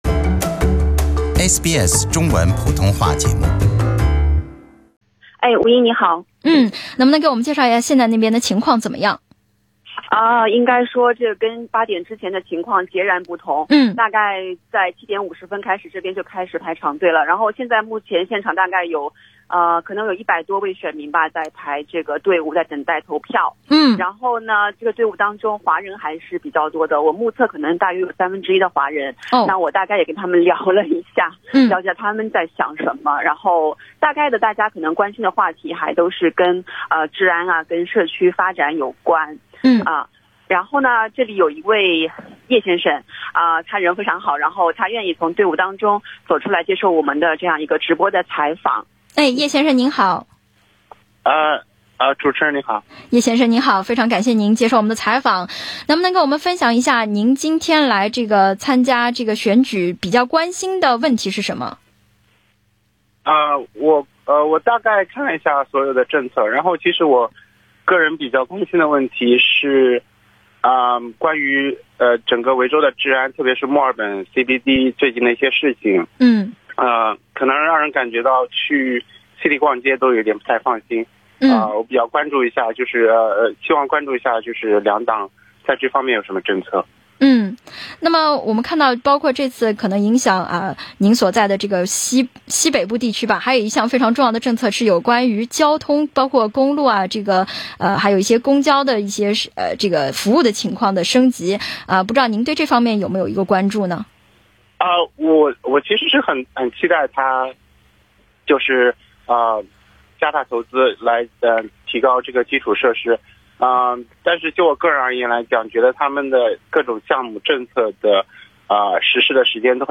SBS普通话广播节目直击墨尔本西南部的Altona选区投票。
点击收听来自现场的报道。